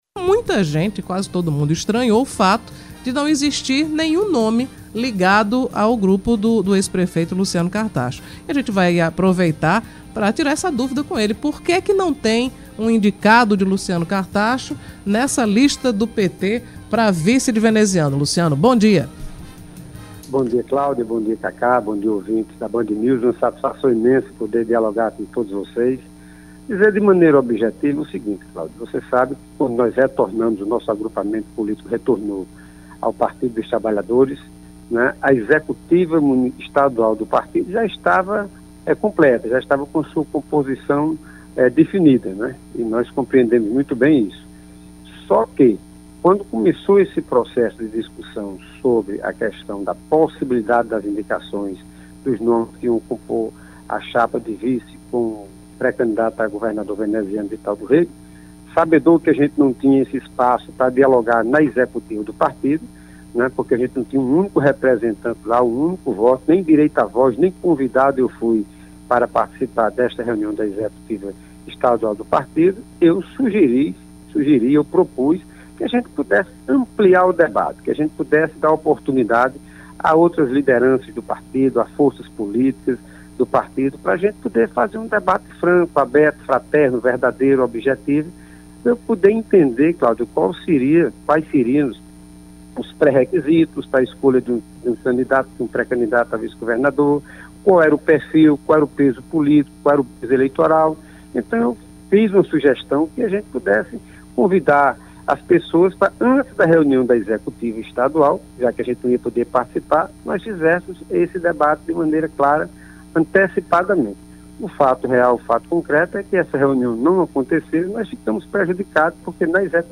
Cartaxo disse à Band News FM que não teve voz no processo.